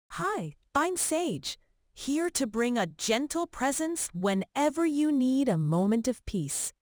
NavTalk 提供多种高质量的语音合成风格，您可以通过 voice 参数自由选择数字人音色：
知性温柔女声